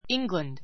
England íŋɡlənd イ ン グ らン ド 固有名詞 ❶ イングランド ⦣ グレートブリテン島 （Great Britain） の南部地方の名.